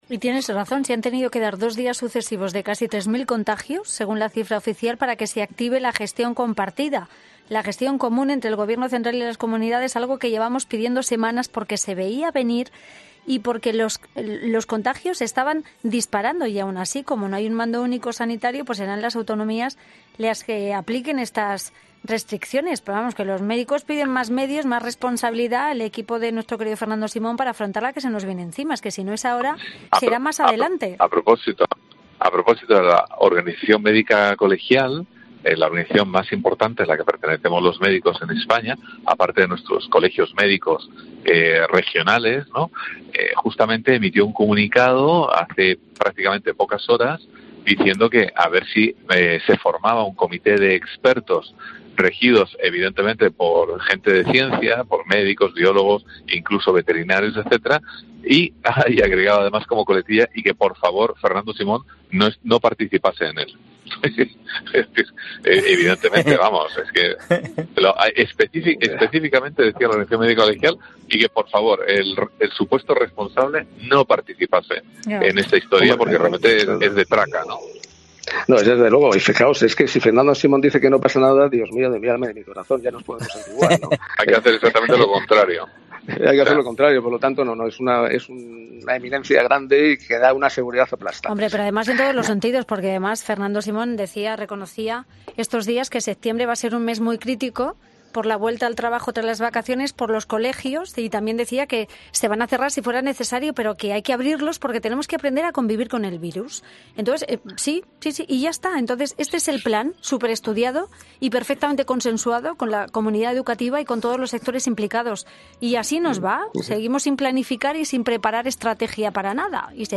Una crítica descarnada que levantaba el asombro y las risas de todos los presentes en la tertulia por la dureza de las palabras.